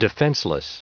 Prononciation du mot defenseless en anglais (fichier audio)
Prononciation du mot : defenseless